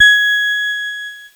Cheese Note 26-G#4.wav